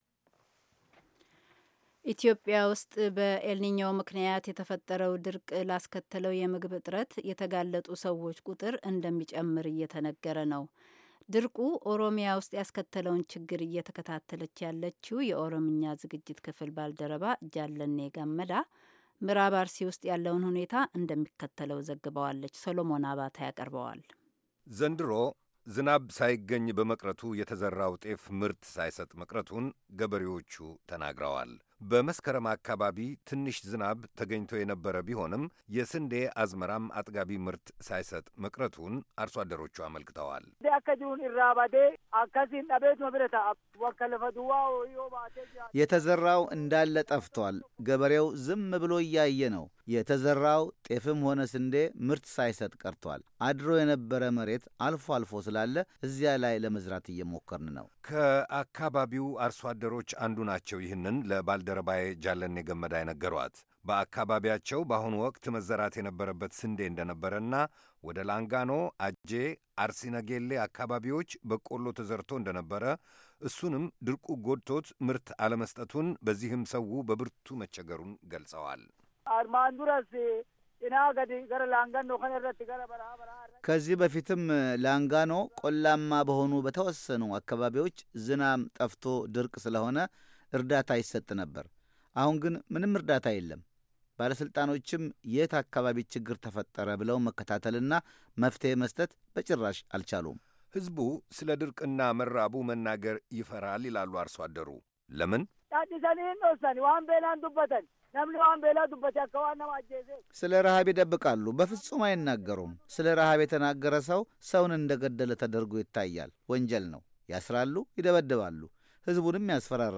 የተዘራው ሁሉ መጥፋቱን ለቪኦኤ የገለፁ አንድ የምዕራብ አርሲ አርሦ አደር የሰሞኑ እርጥበት ሊያበቅልልን ይችላል በሚል ተስፋ ያደረ መሬት ለማረስና ለመዝራት እየሞከሩ መሆናቸውን ተናግረዋል፡፡
እነዚህን ክሦች አስተባብለው ለቪኦኤ መግለጫ የሰጡት የምዕራብ አርሲ አስተዳዳሪ አቶ አባድር አብዳ መንግሥት “የአንድም ሰው ሕይወት መጥፋት የለበትም የሚል ፖሊሲ ይዞ እየሠራ ነው” ብለዋል፡፡